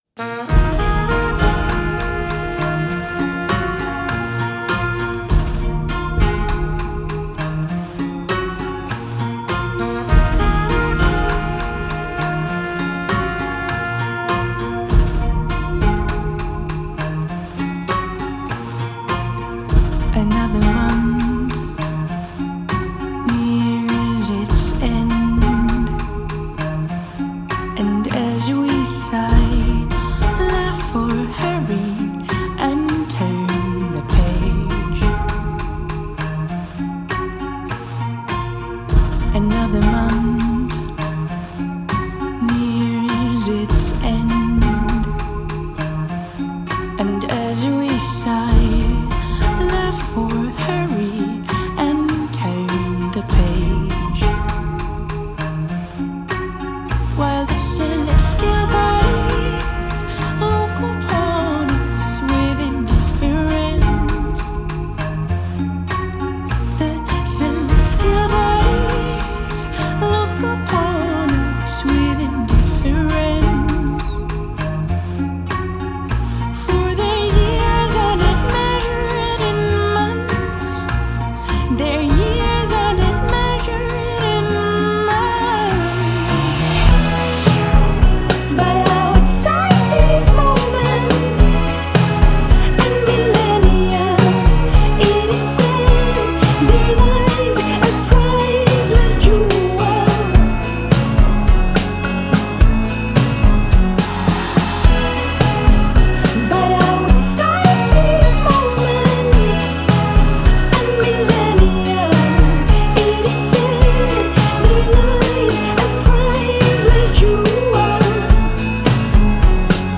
Genre: Indie Pop, Female Vocal, Experimental